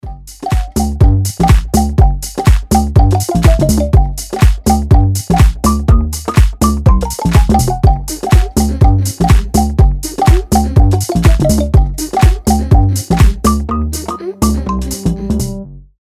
• Качество: 320, Stereo
забавные
веселые
Electronic
Tech House